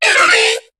Cri de Muciole dans Pokémon HOME.